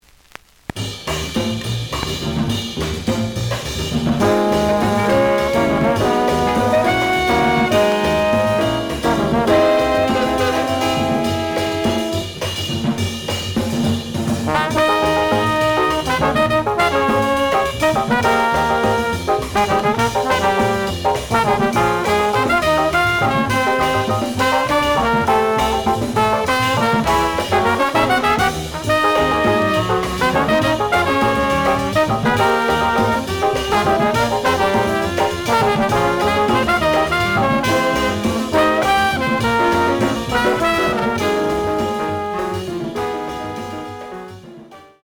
The audio sample is recorded from the actual item.
●Genre: Post Bop